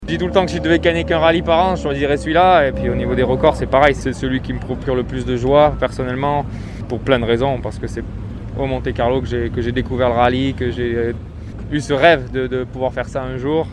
Le rallye lui tenait particulièrement à coeur explique-t-il au micro de Nice Radio ⬇